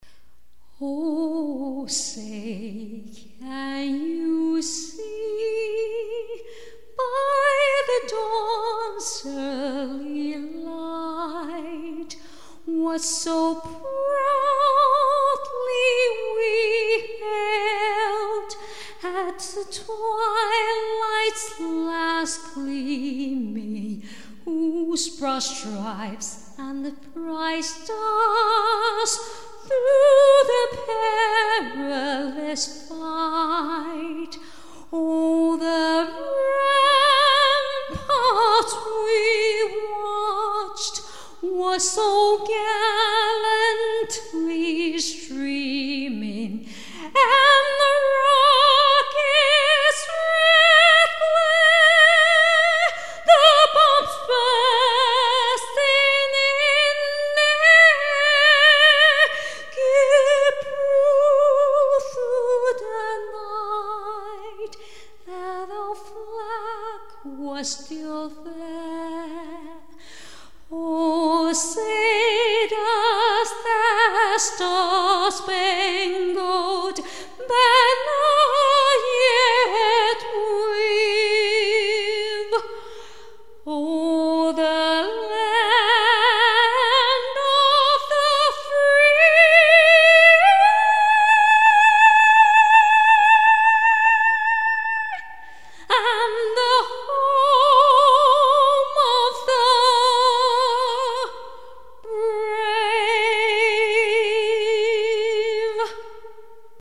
再唱美国国歌！Happy 4th of July